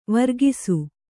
♪ vargisu